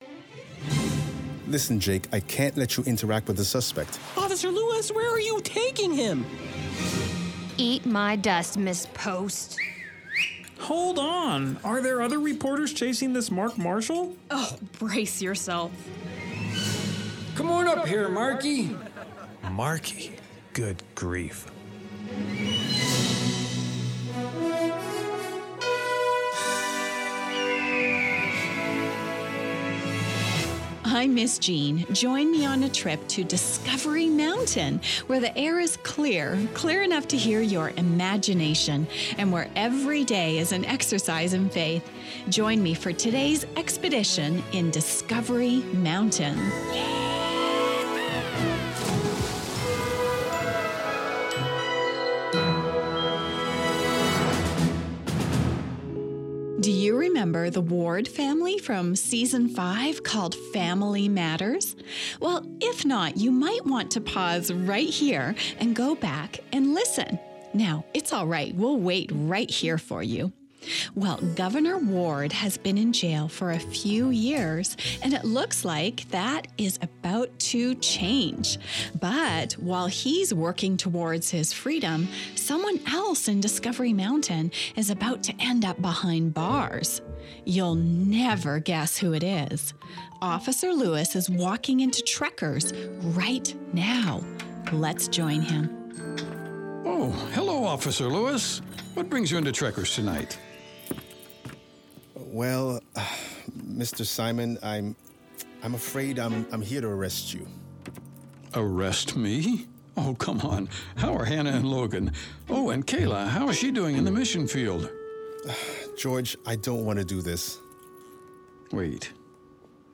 A dramatized audio program where listeners experience adventure, mystery, camp fire songs and, most importantly, get to know Jesus.